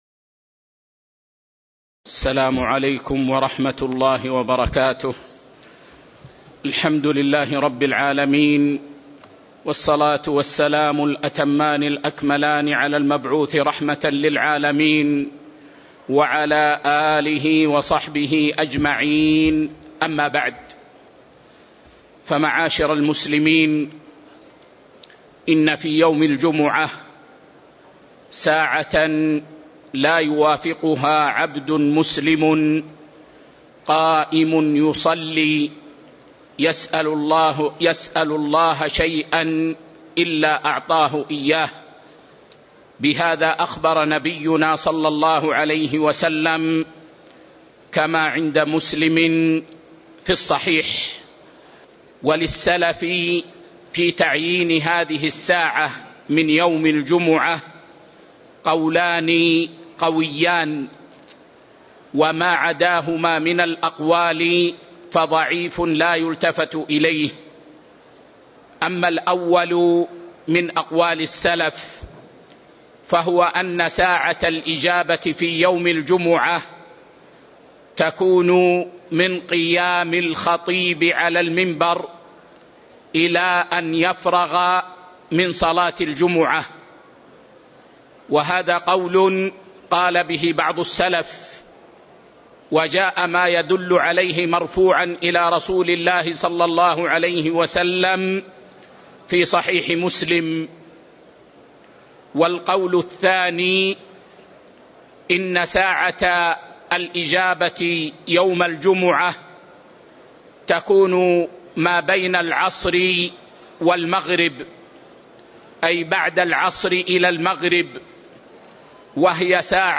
تاريخ النشر ٥ ربيع الثاني ١٤٤٥ هـ المكان: المسجد النبوي الشيخ